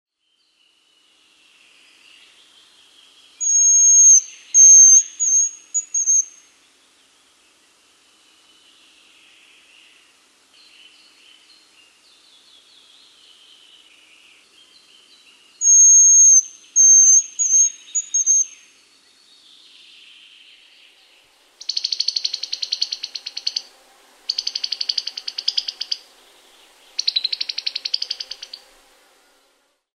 Pyyn ääni
Äänitellä kuulet pyyn laulua ja varoitusääntä.